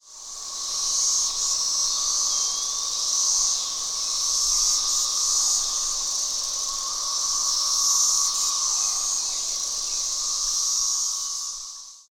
The song of this species is quite distinctive, and we soon heard them singing. The song sounds a bit like "pha.......raoh!", with the "pha....." part very high and drawn out and the "raoh" part dropping suddenly at the end of the call.
this recording we made a few years ago. The "pha....." parts of the numerous cicadas singing tends to blend together so that you may first notice just the dropping-in-pitch "raoh!" note.